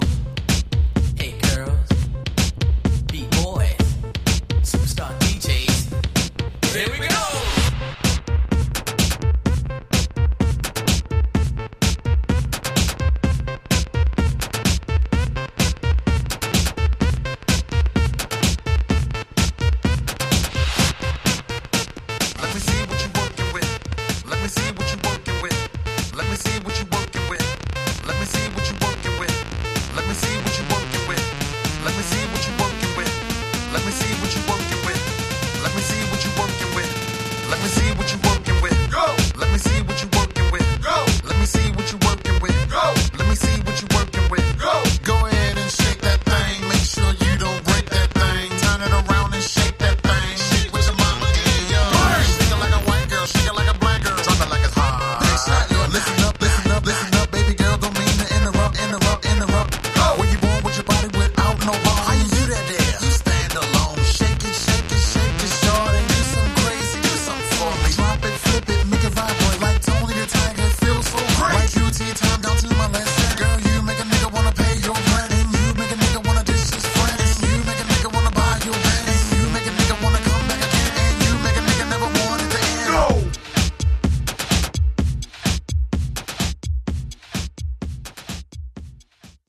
126 bpm
Dirty Version